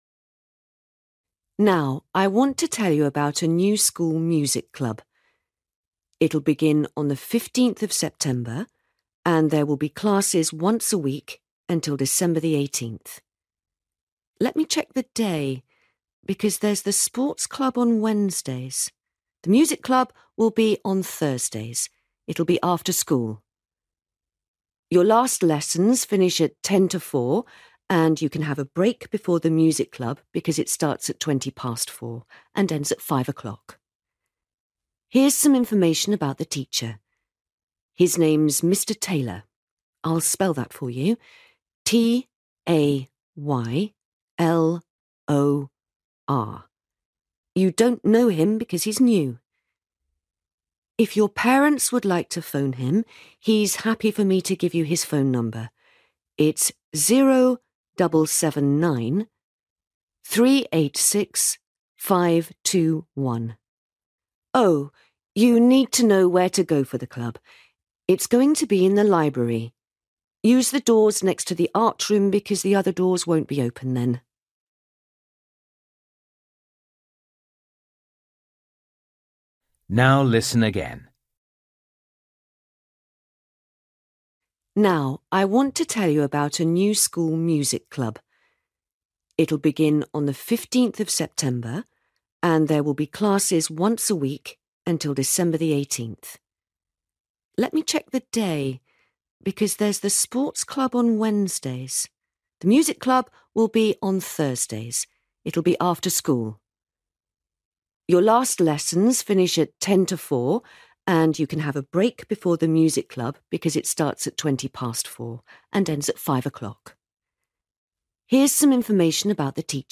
You will hear a teacher talking to a class about a new music club.